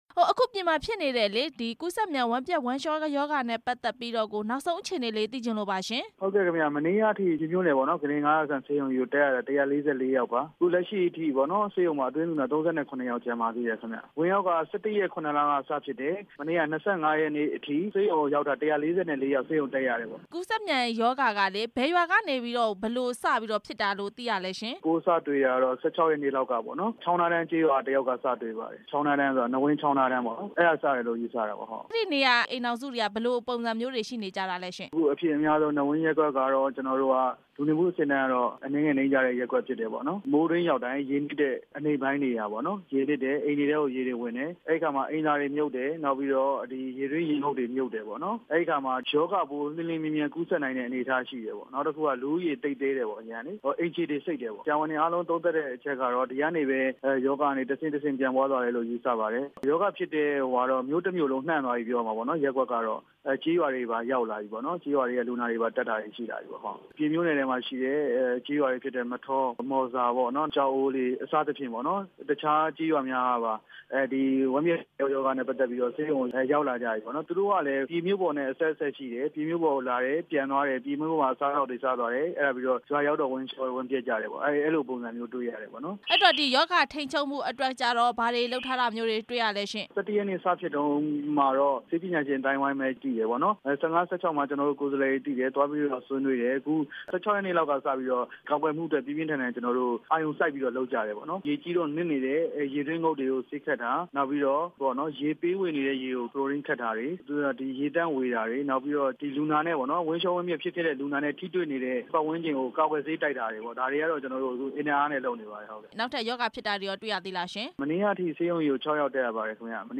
ပြည်မြို့မှာ ဝမ်းပျက်ဝမ်းလျှောရောဂါ ဖြစ်နေတဲ့အကြောင်း မေးမြန်းချက်